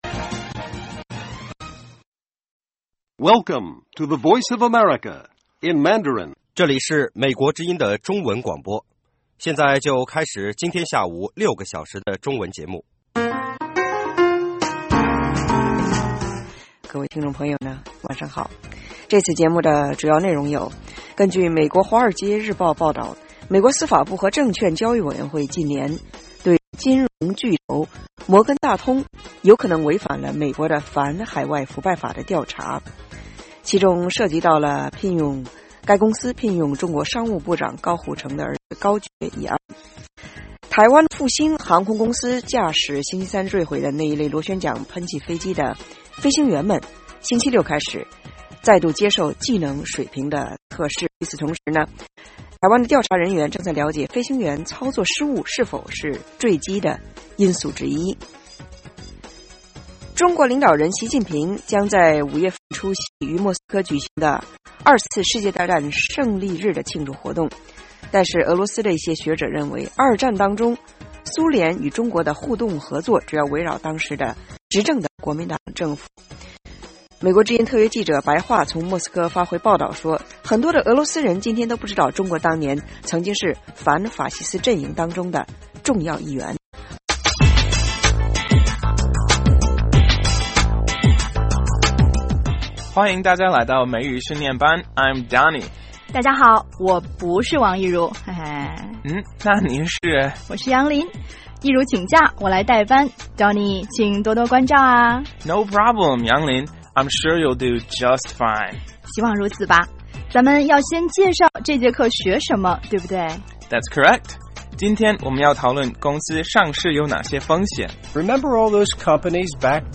北京时间下午5-6点广播节目。 内容包括国际新闻和美语训练班(学个词， 美国习惯用语，美语怎么说，英语三级跳， 礼节美语以及体育美语0。